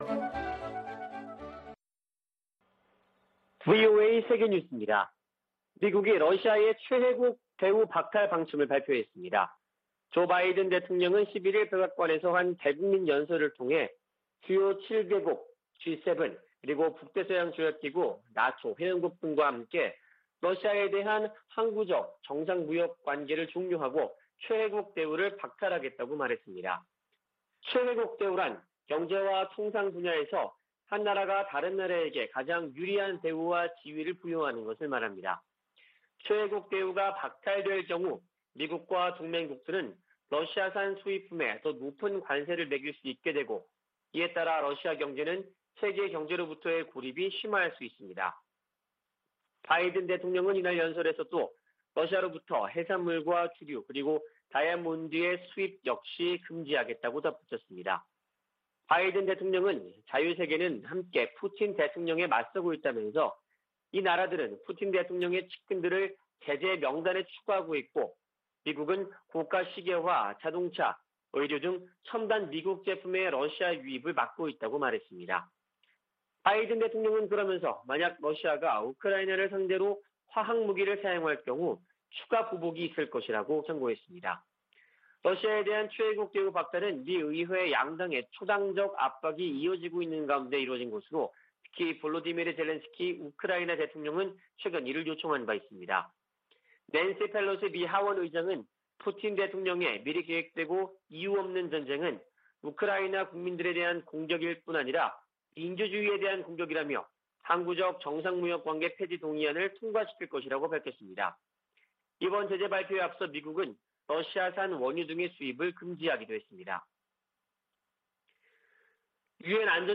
VOA 한국어 아침 뉴스 프로그램 '워싱턴 뉴스 광장' 2022년 3월 12일 방송입니다. 미 국무부는 북한 핵·미사일 위협을 한국의 윤석열 차기 정부와 핵심 협력 사안으로 꼽았습니다. 미국 주요 언론은 한국에 보수 정부가 들어서면 대북정책, 미한동맹, 대중국 정책 등에서 큰 변화가 있을 것으로 전망했습니다. 북한이 폭파했던 풍계리 핵실험장을 복구하고 금강산에 있는 한국 측 시설을 철거하는 움직임이 포착된 것으로 알려졌습니다.